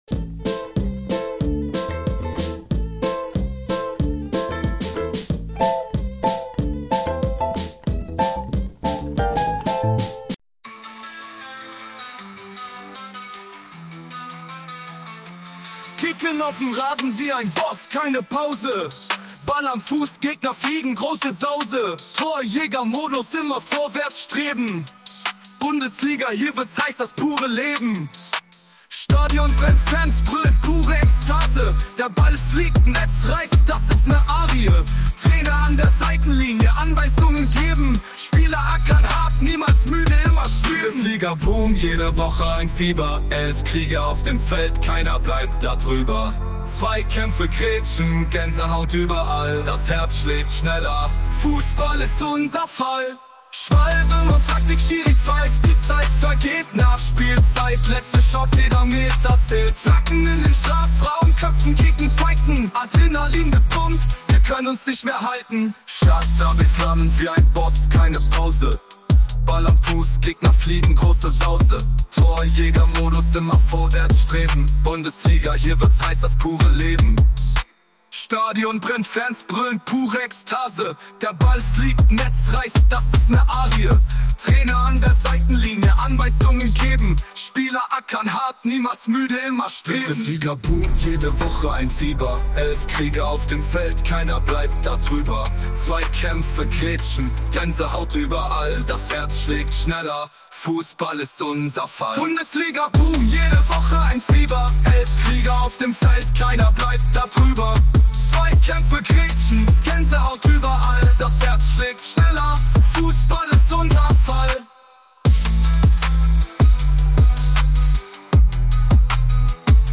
Jetzt mit Rap!!!!